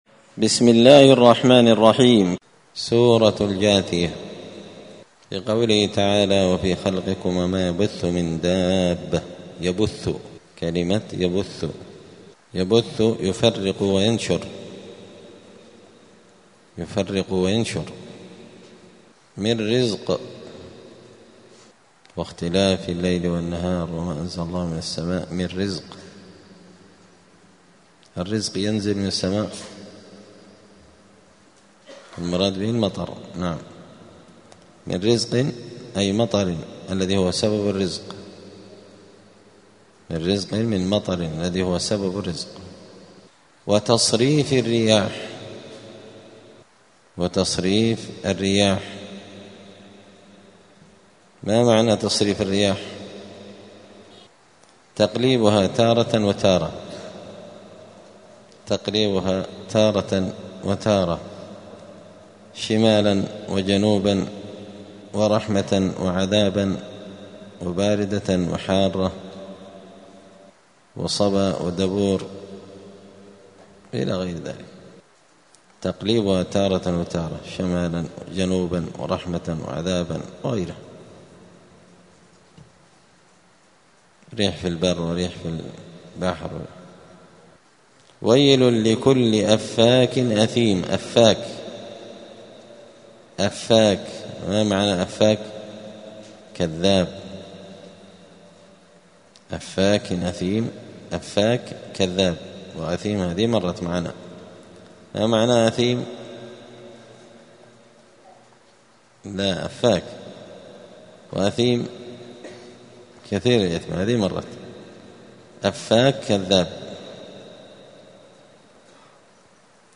الأربعاء 13 شعبان 1446 هــــ | الدروس، دروس القران وعلومة، زبدة الأقوال في غريب كلام المتعال | شارك بتعليقك | 28 المشاهدات